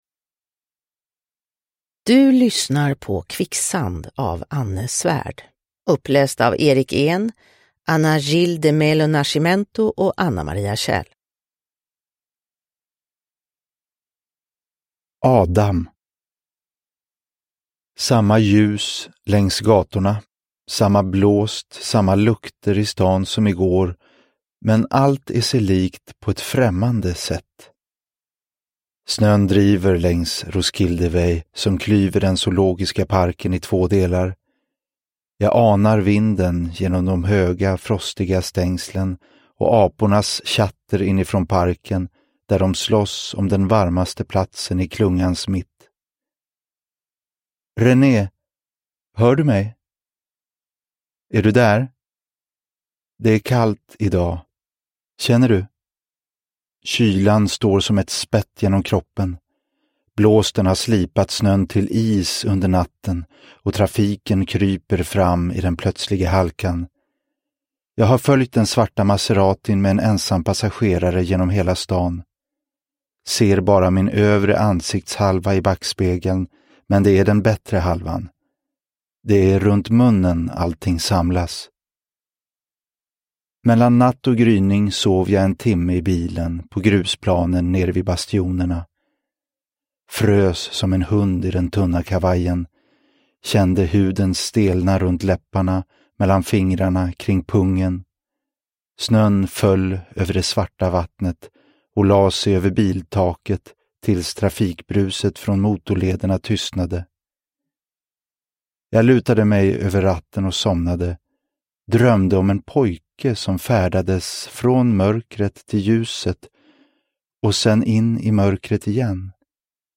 Kvicksand – Ljudbok – Laddas ner